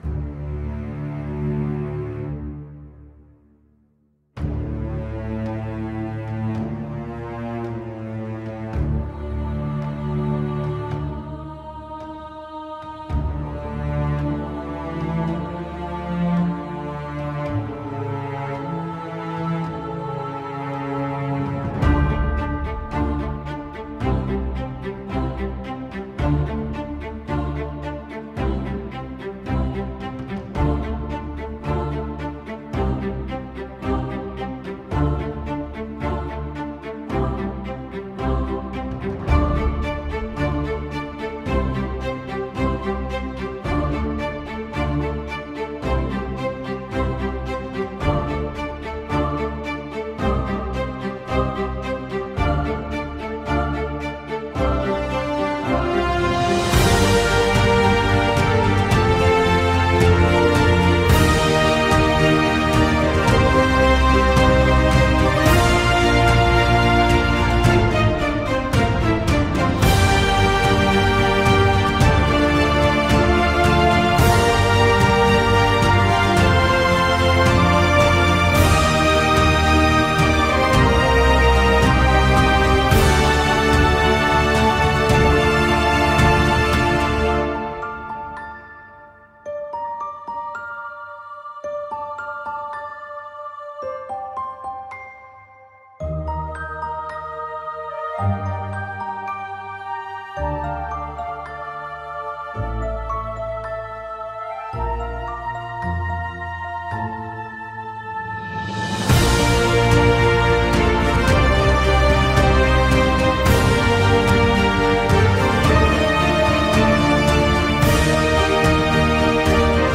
ダークで英雄的なファンタジー・オーケストラ、重厚な太鼓のパーカッション、高らかに響くバイオリン、荘厳なホルン、神秘的で幻想的な合唱、壮大な映画のようなサウンドスケープ、テンポ110bpm
神秘的かつ力強く、物語のクライマックスを感じさせる一曲。